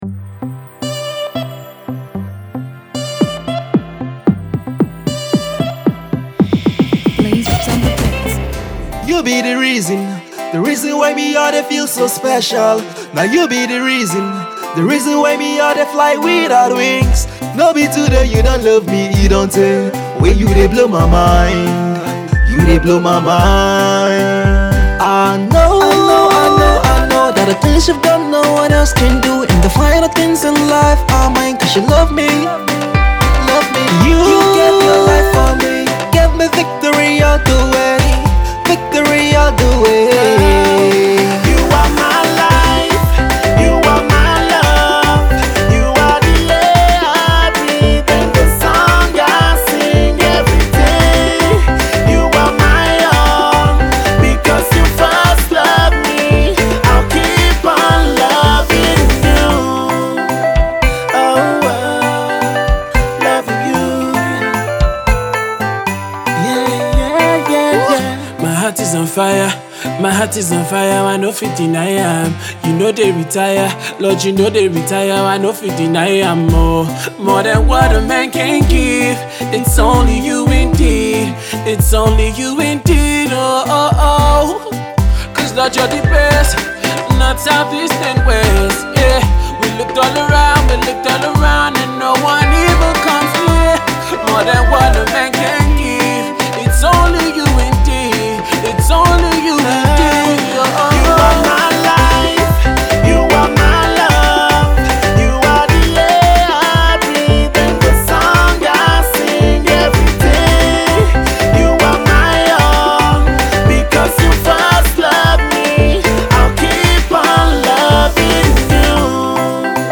The self produced track is an upbeat love song to God.